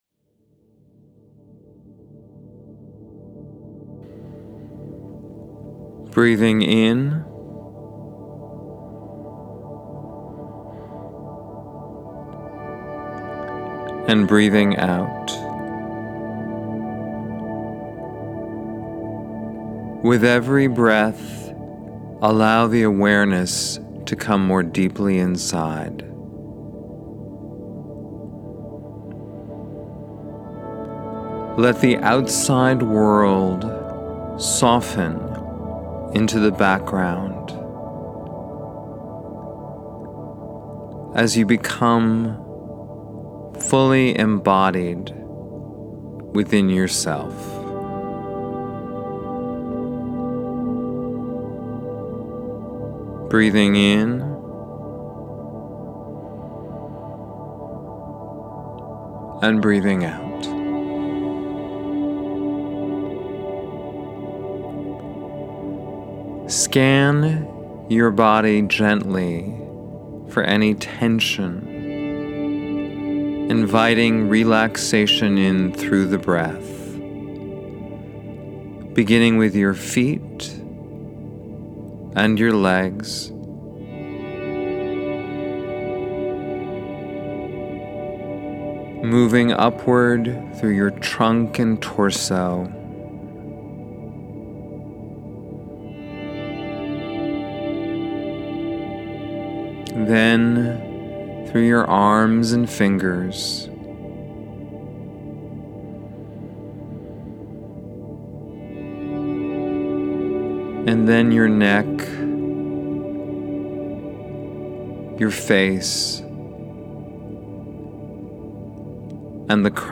Embodiment-Meditation.mp3